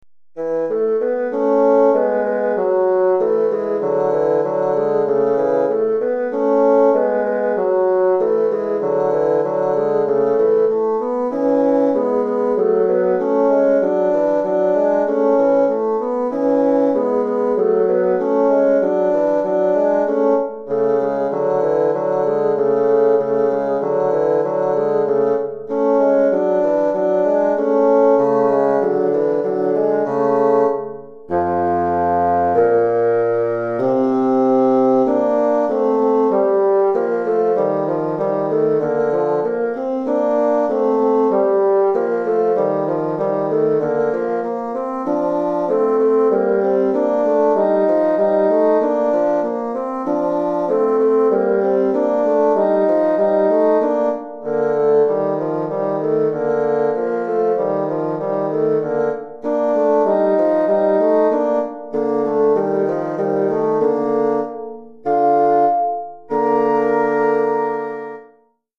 Recueil pour Basson - 2 Bassons